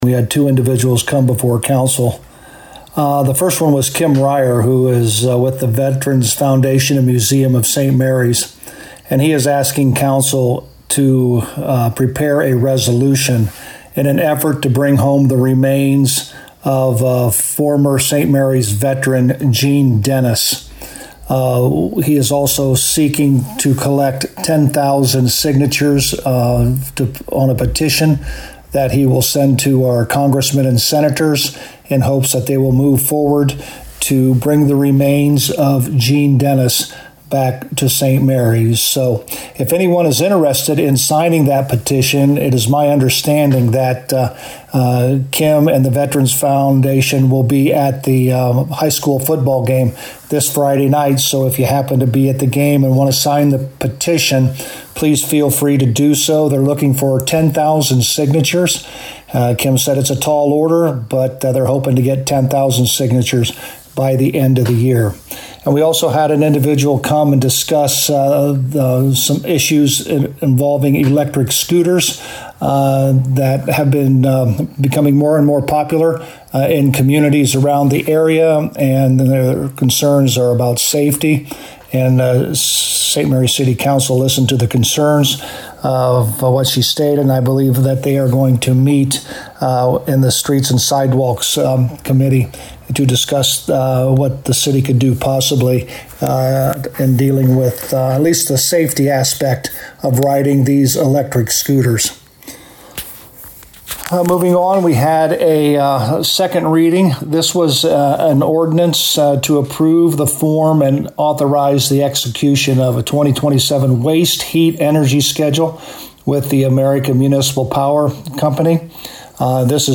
To hear Mayor Hurlburt's Summary of the Council Meeting: